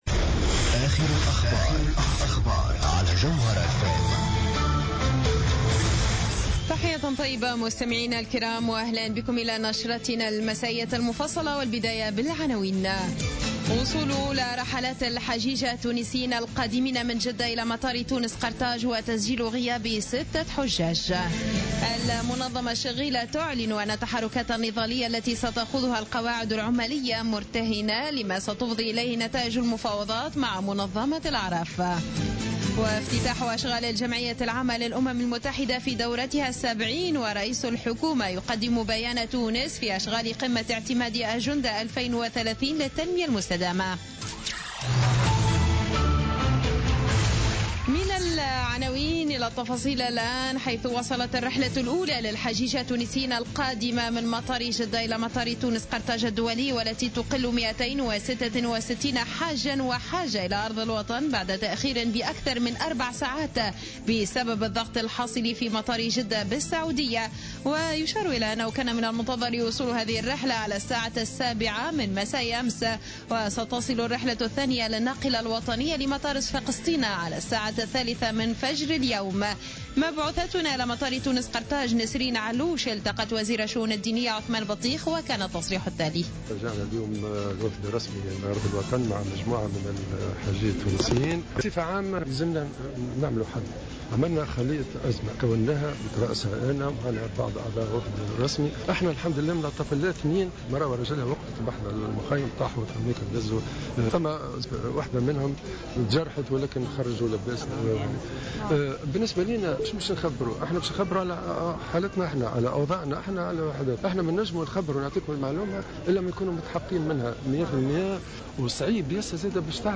نشرة أخبار منتصف الليل ليوم الثلاثاء 29 سبتمبر 2015